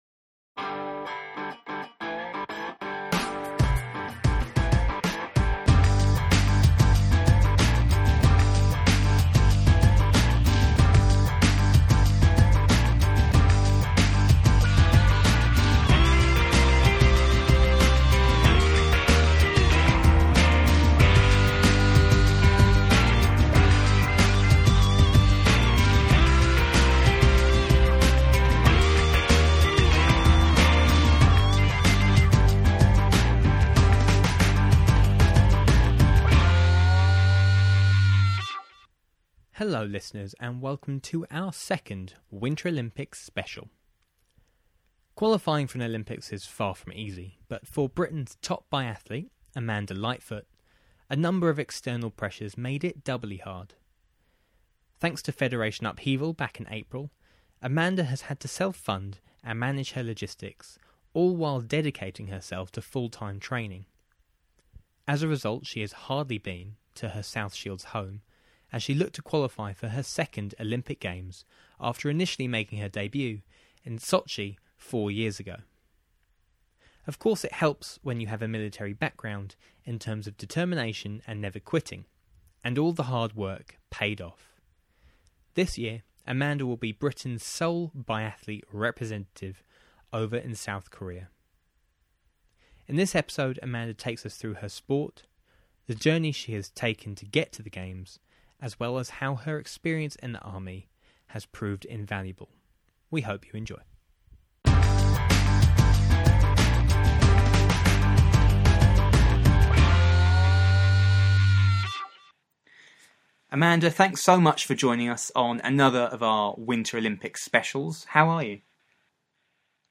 It has been far from easy but the hard work has more than paid off. In this one-on-one interview